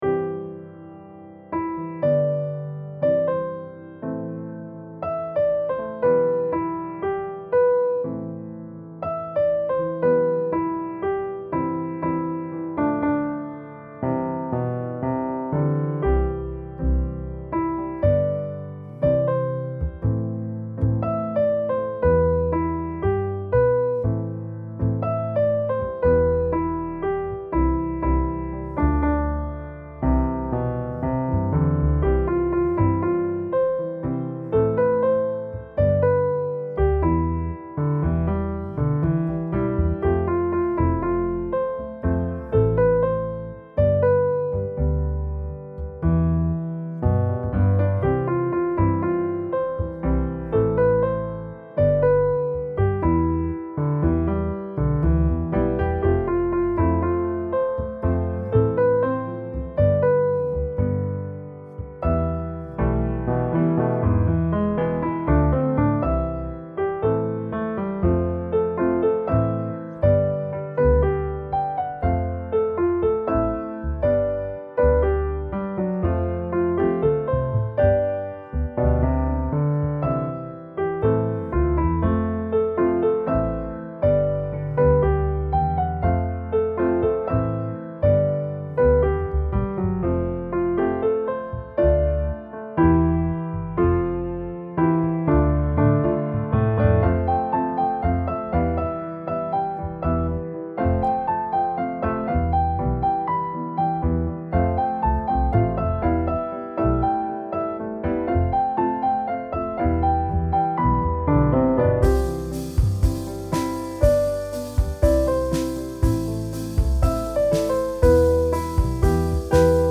R&BBallad